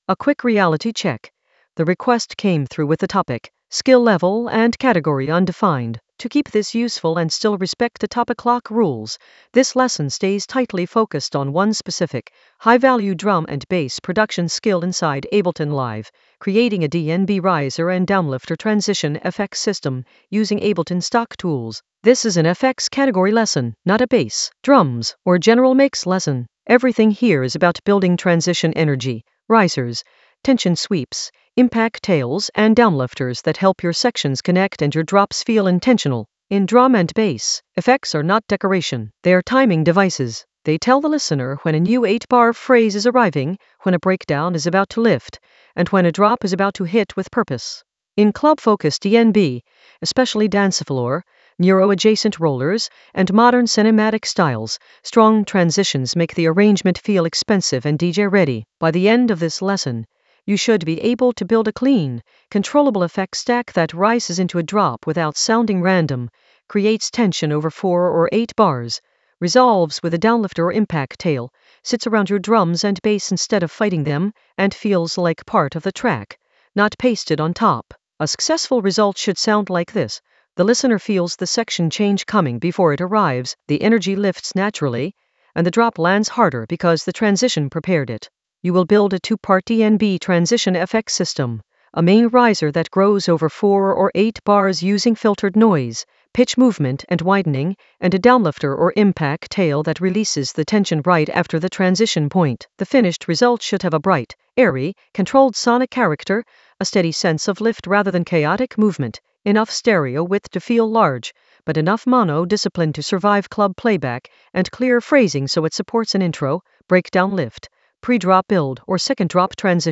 Narrated lesson audio
The voice track includes the tutorial plus extra teacher commentary.
An AI-generated beginner Ableton lesson focused on Vocoder Rasta style voices and how to use it like a pro in Ableton Live 12 in the FX area of drum and bass production.